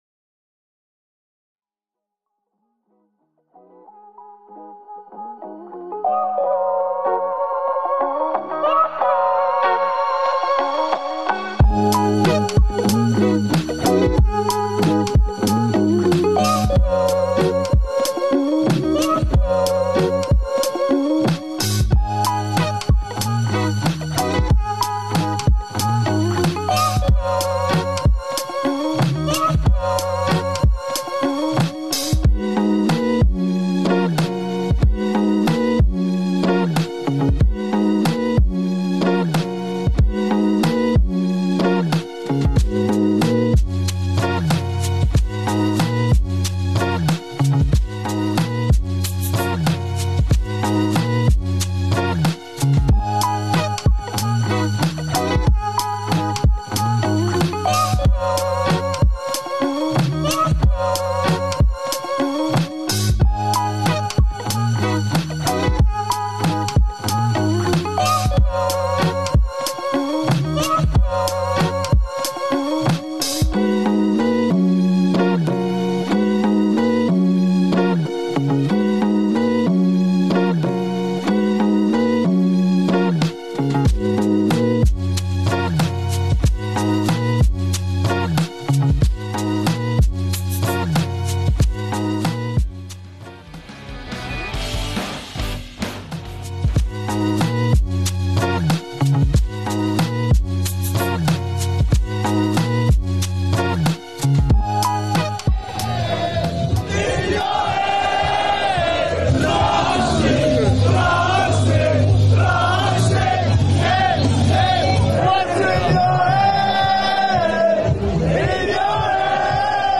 28 Oct Inside Pass LIVE from Foresters Arms: The final countdown to Boks' date with destiny starts now